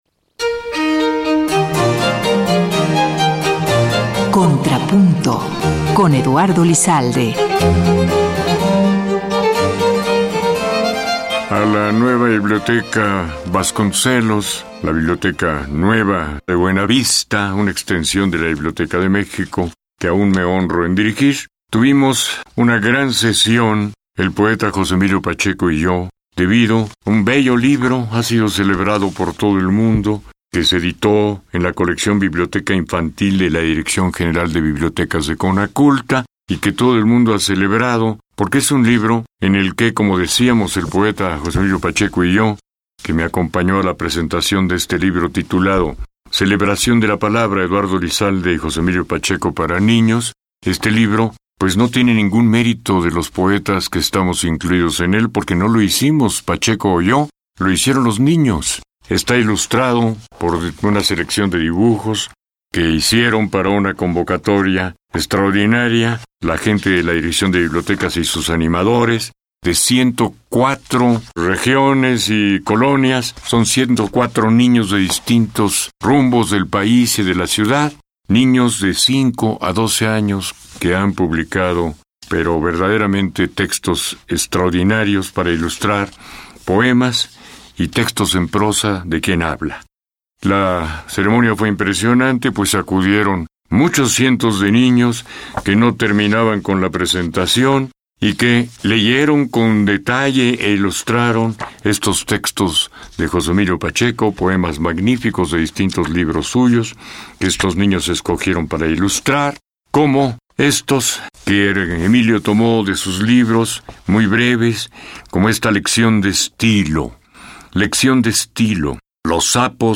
Su conocimiento profundo sobre ópera y los más variados temas que conforman la cultura nacional y universal, su sensible e inigualable pluma, su rotunda y contundente voz, ensanchan las transmisiones de la radio pública y engalanan las emisoras del IMER, especialmente Opus 94.5 FM.